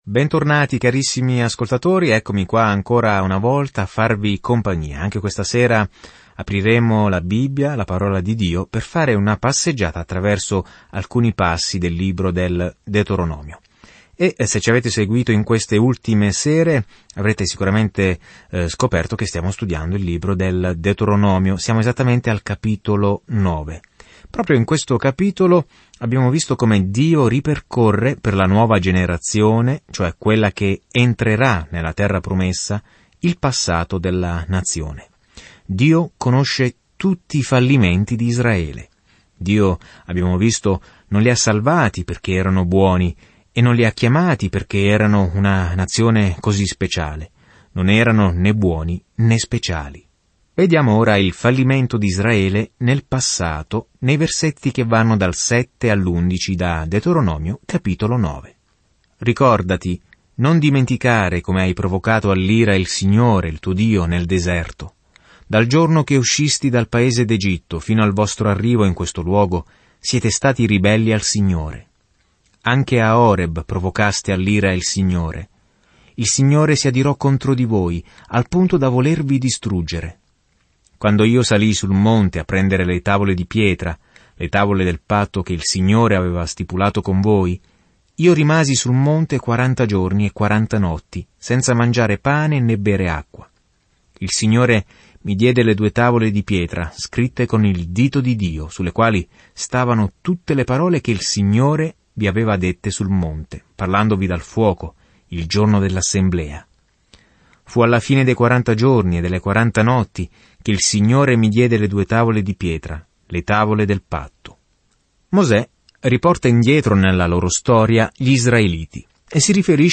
Scrittura Deuteronomio 9:7-29 Deuteronomio 10:1-13 Giorno 8 Inizia questo Piano Giorno 10 Riguardo questo Piano Il Deuteronomio riassume la buona legge di Dio e insegna che l'obbedienza è la nostra risposta al suo amore. Viaggia ogni giorno attraverso il Deuteronomio mentre ascolti lo studio audio e leggi versetti selezionati della parola di Dio.